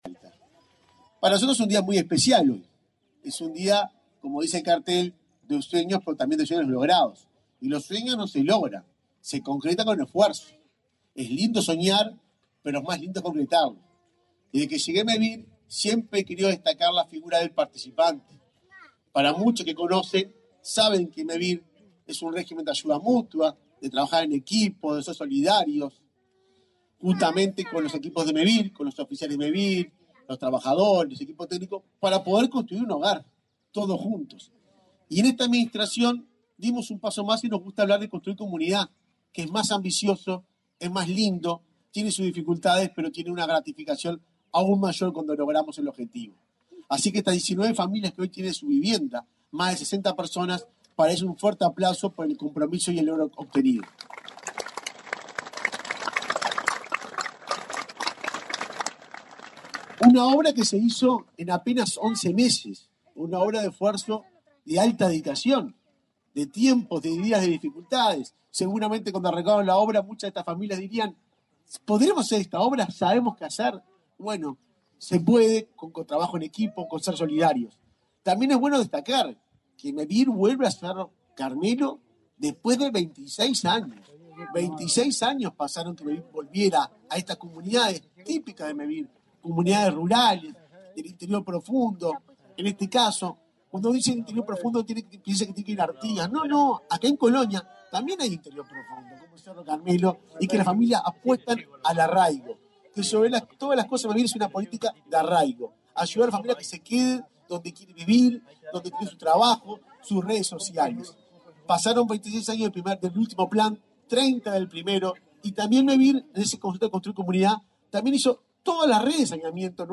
Palabras de autoridades en acto en Mevir
El presidente de Mevir, Juan Pablo Delgado, y el subsecretario de Vivienda, Tabaré Hackenbruch, participaron en la inauguración de 19 viviendas en